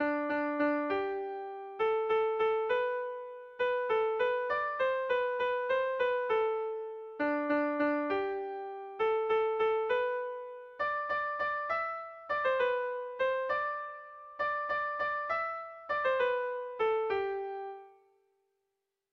Erlijiozkoa
ABADE